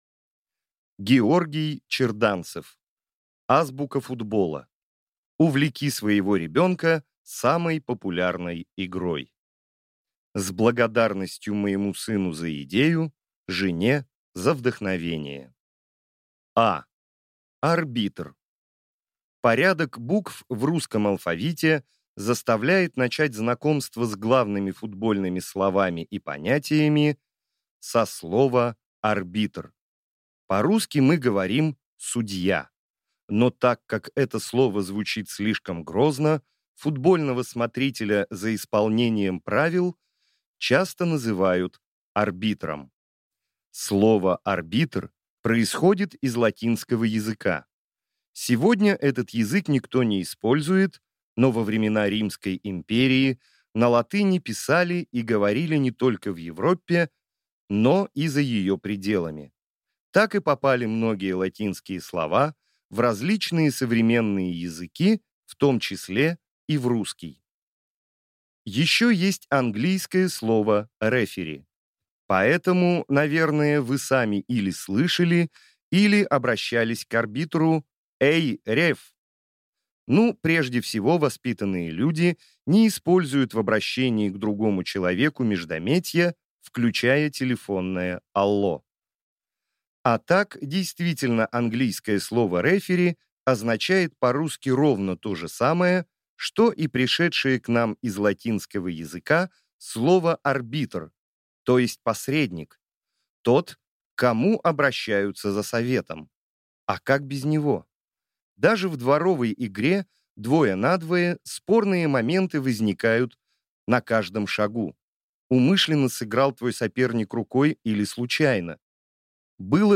Аудиокнига Азбука футбола. Увлеки своего ребёнка самой популярной игрой!